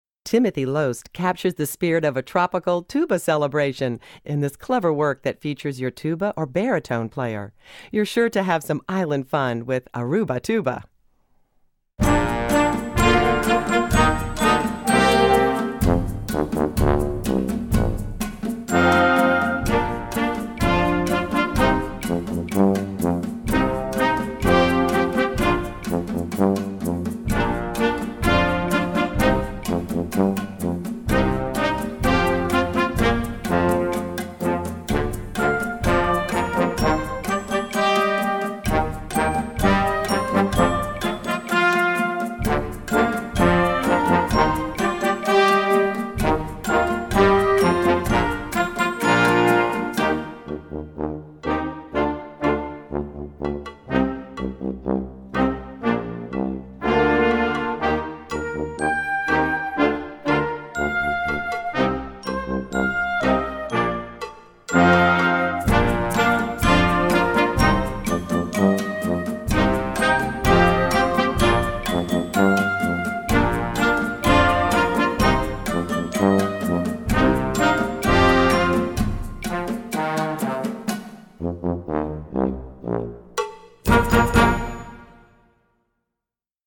Voicing: Tuba Section w/ Band